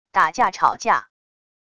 打架吵架wav音频